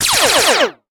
enemylasers3.ogg